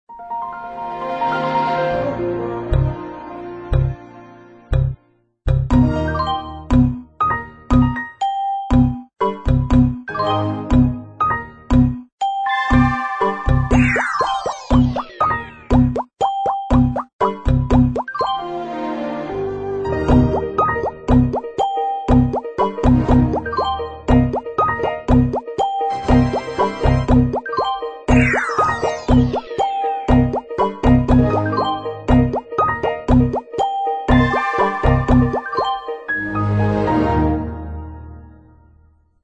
就是巧妙的讲XP中所有的音效给巧妙/有机的编配合成. 产生的一段Windows Xp Break Beat!